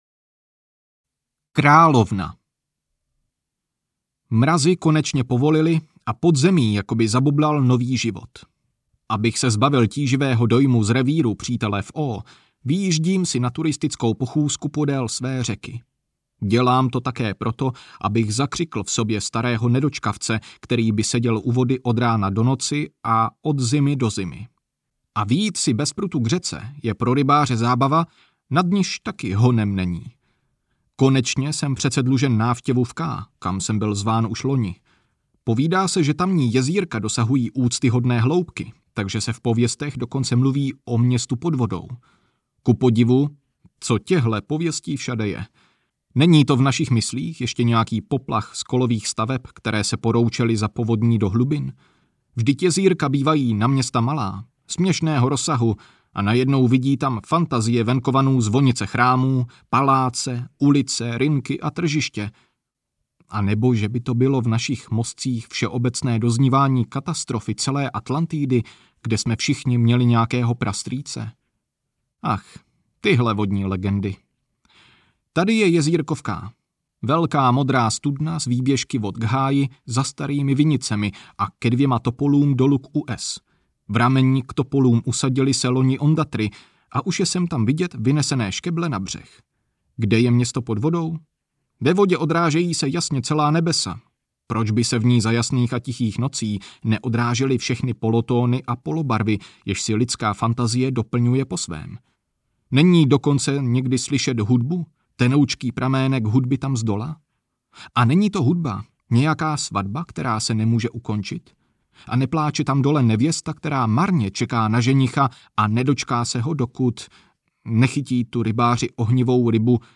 Rybářská knížka audiokniha
Ukázka z knihy